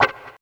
137 GTR 8 -R.wav